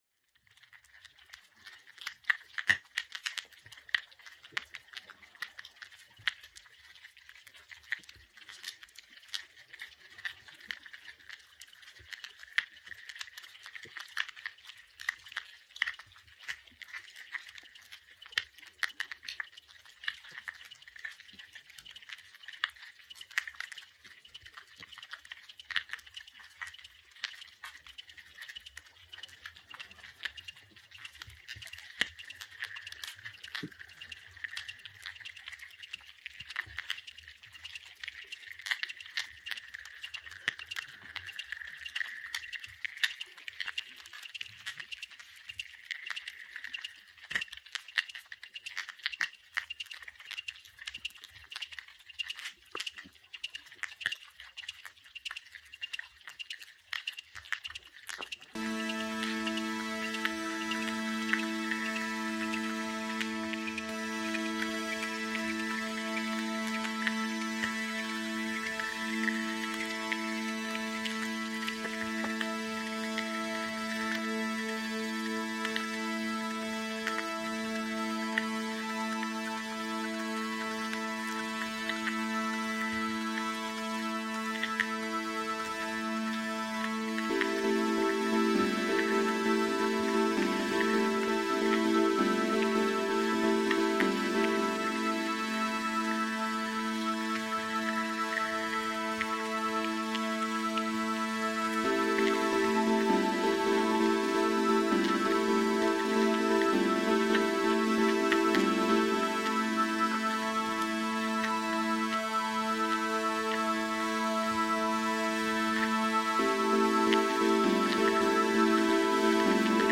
Hydrophone recording, Tremiti Islands